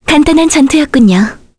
Selene-Vox_Victory_kr.wav